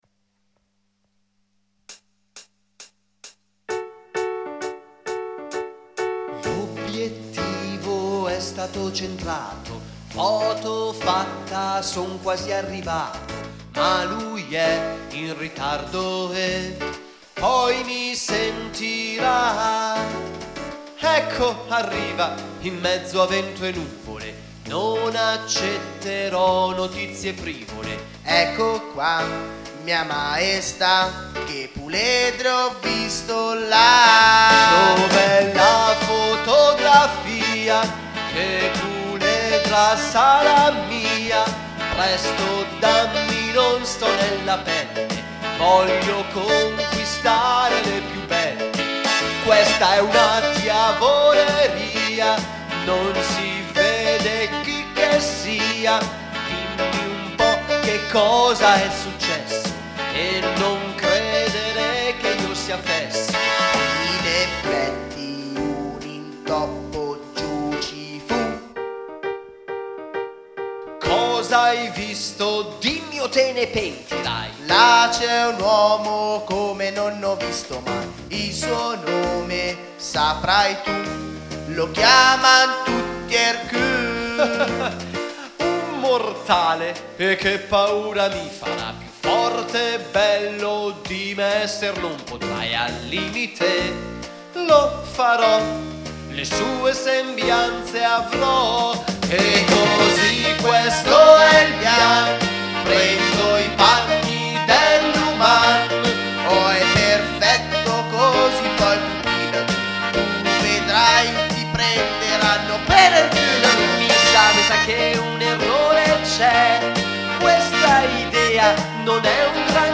Trovate in questa sezione alcuni dei brani cantati durante lo spettacolo.